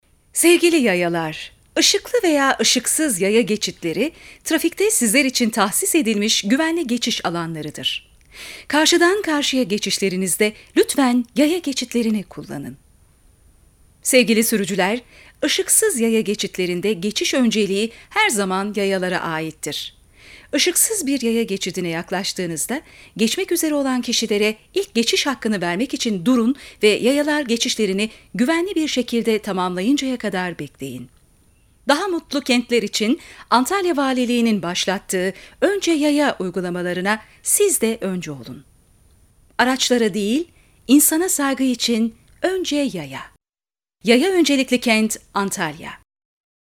Önce Yaya Kamu Spotu (Radyo-Kadın)